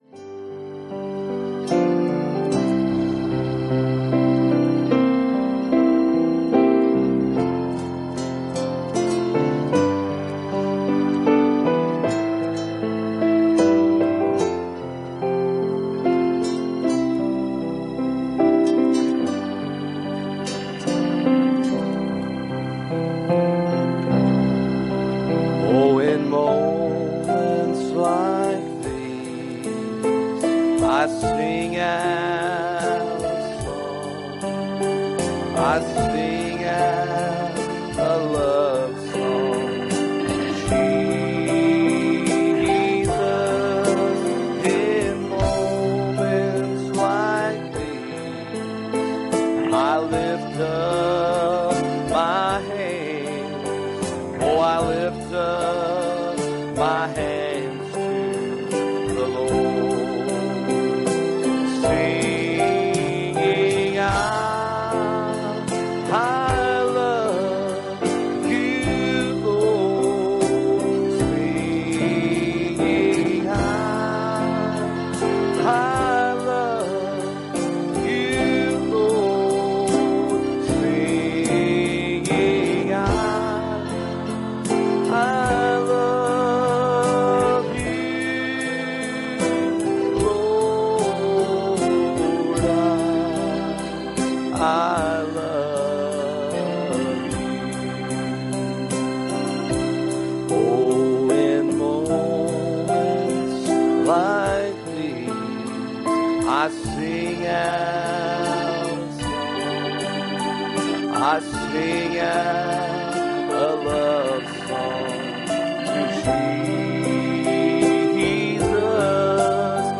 Acts 9:26 Service Type: Special Service Bible Text